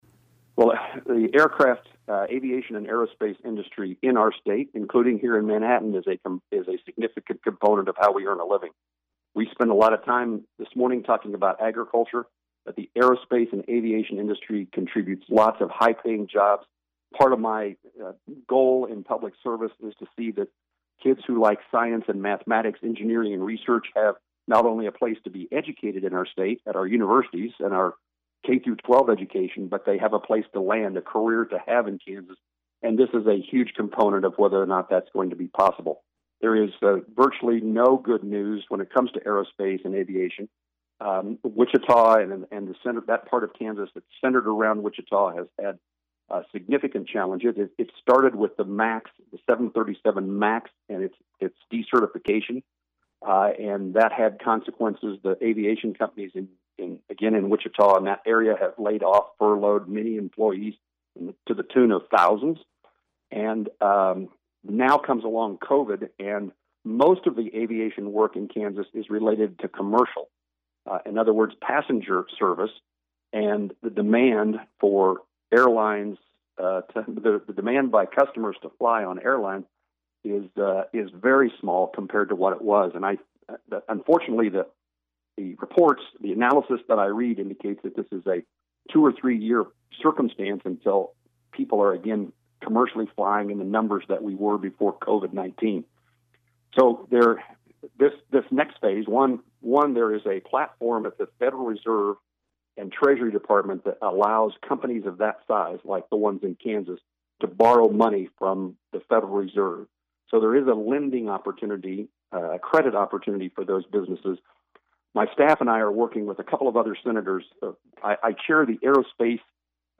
COVID-19 Q & A with U.S. Senator Jerry Moran